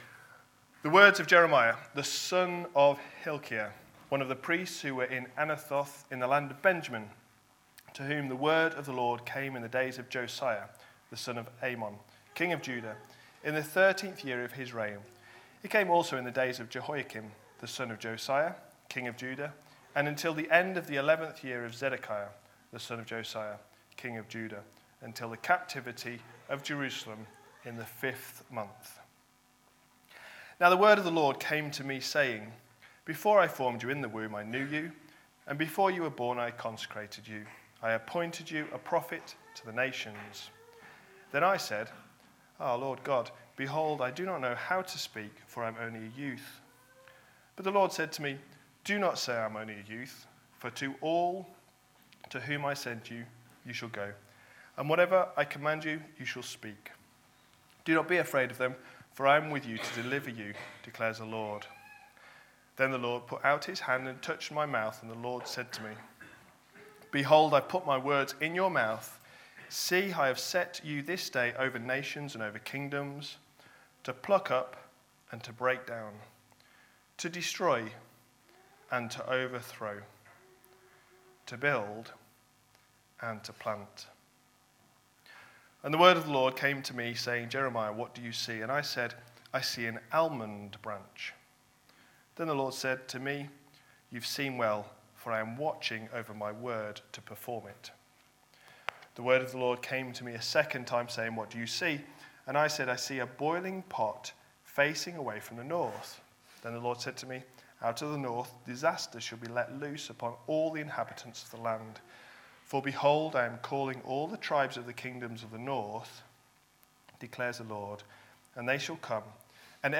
A sermon preached on 13th January, 2019, as part of our Jeremiah series.